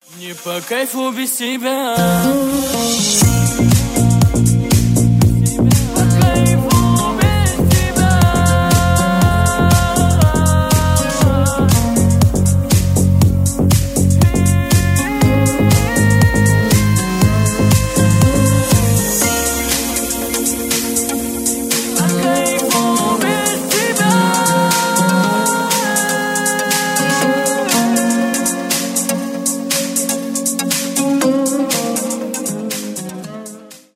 восточные , на бывшего , танцевальные , шансон , русские